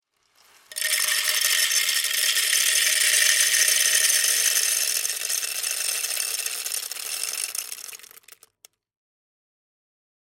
Bar and Coffee Shop – Coffee Machine, Spilling Coffee Beans
This sound effect comes with the added ambiance to fit either in your home machine or your local café.
SpillAudio-Bar-and-Coffee-Shop-Coffee-Machine-Spilling-Coffee-Beans.mp3